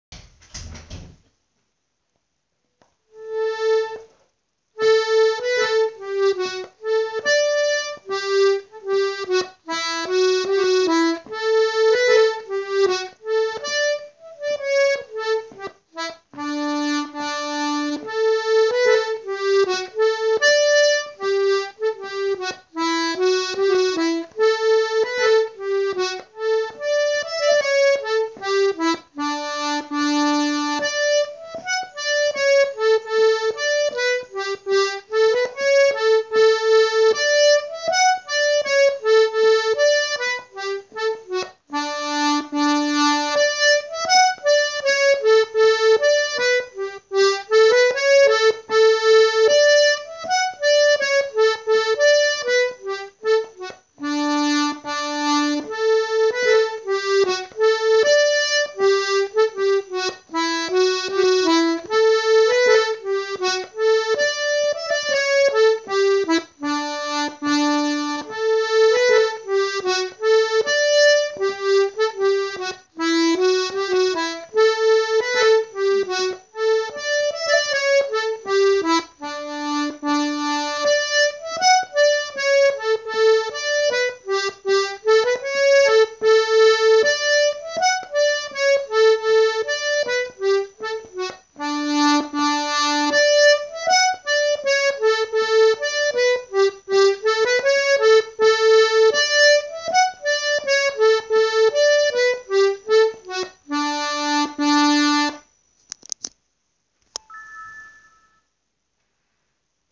Bhí na píosaí casta go han-mhall ar an dtaifead sin ach tá siad anseo arís- casta ag gnáthluas agus casta níos fearr!!